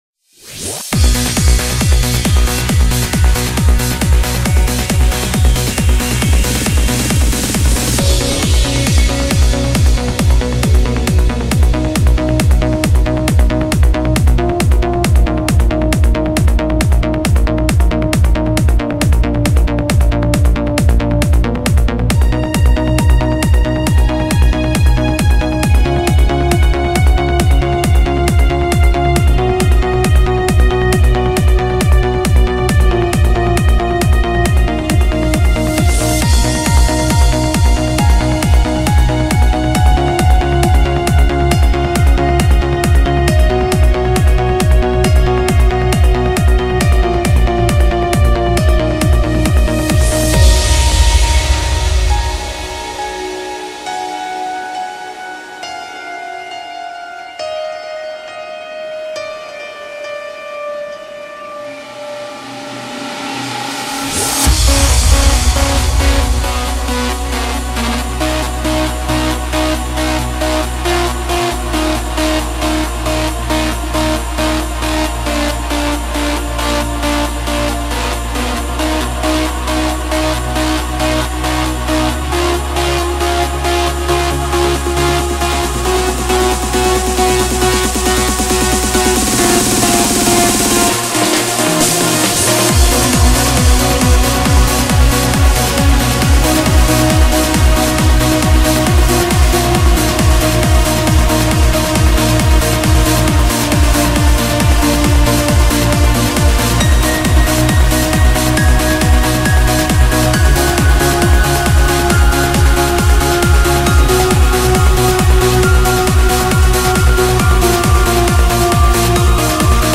BPM17-136
Audio QualityPerfect (High Quality)
Comments[EMOTIONAL TRANCE]